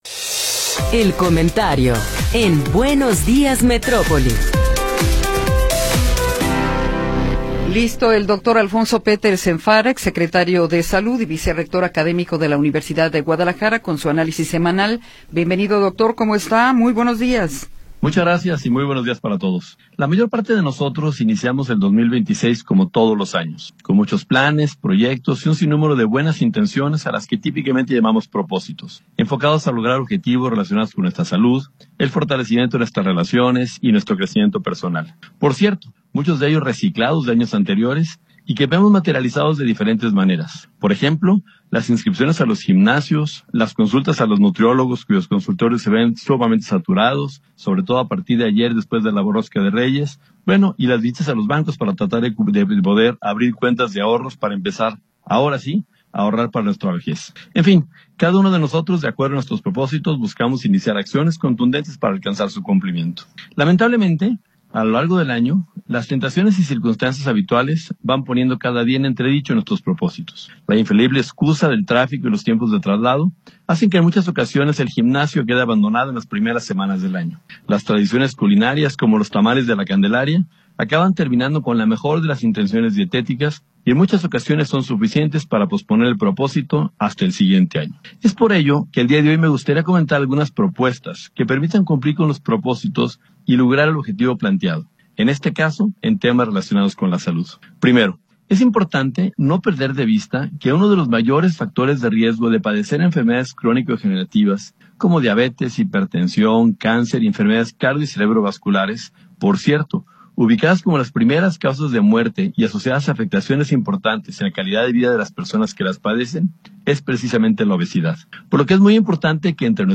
Comentario de Alfonso Petersen Farah